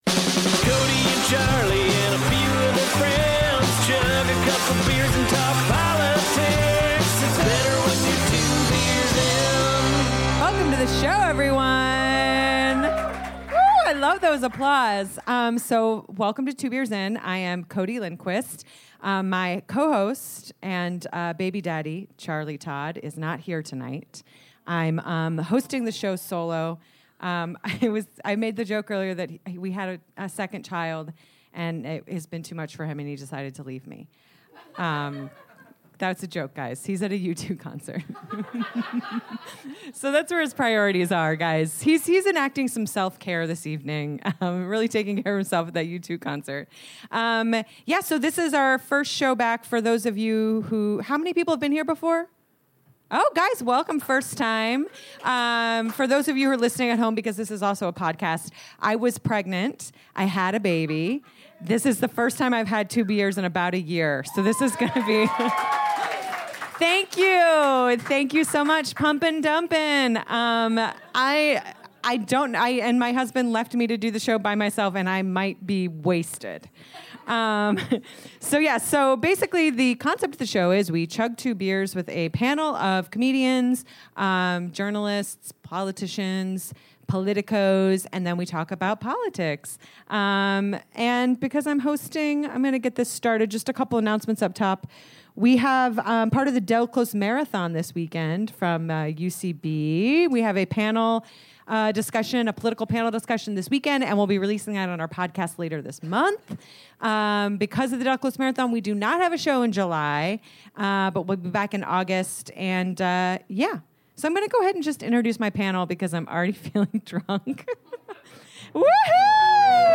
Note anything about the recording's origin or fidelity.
live from the UCB Theatre East Village.